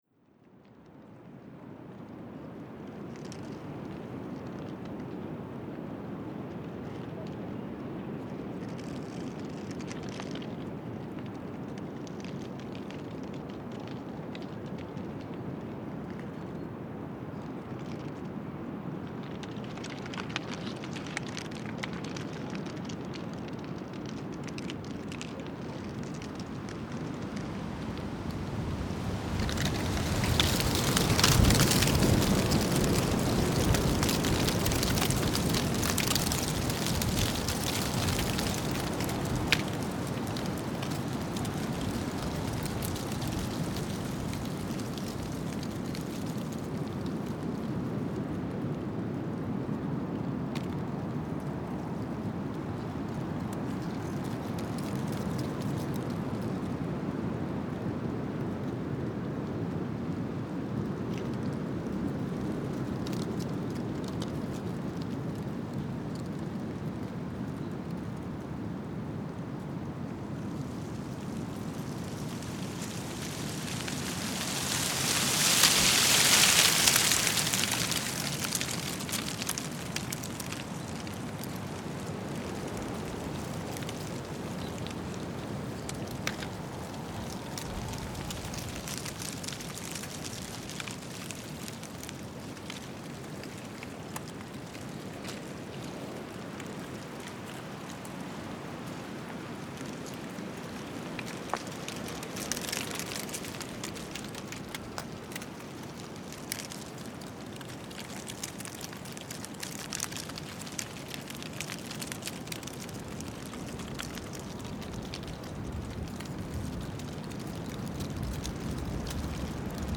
Headlights swayed as the car rolled down the slope, manoeuvring between the potholes and boulders. I waved and began to climb through the rain. Reaching a squat plateau some 400 metres below the summit, I unshoulder my dripping backpack and crawl through the stunted trees towards the edge. The wind gusts and buffets, drags tears from the corners of my eyes, rattles my cagoule’s hood, groans branches and flickers sodden leaves, offering itself as sound but also dragging scraps from the village below the lip of the cliff: a squashed dog bark, a stretched snatch of birdsong, a distended bell.
Field Recording Series by Gruenrekorder